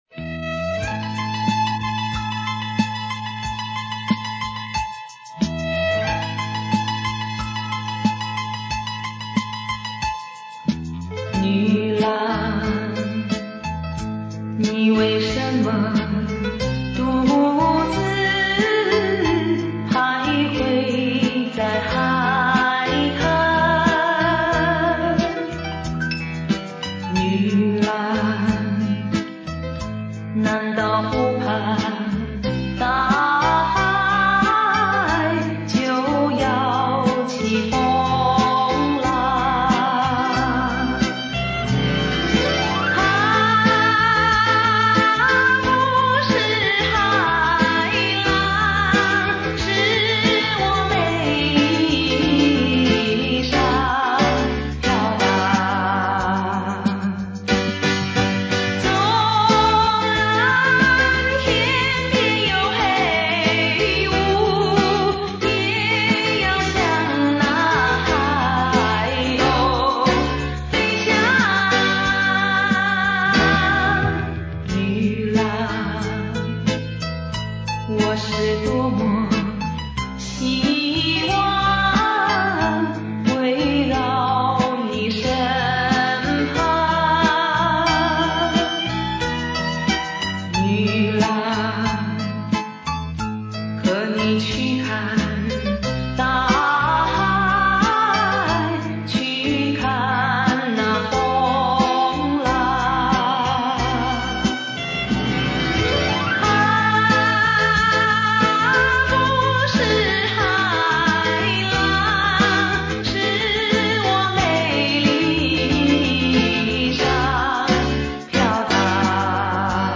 我收藏了这盒磁带，利用随身听和电脑抓轨制作的MP3，音质一般凑活听吧，好歹也算个资源。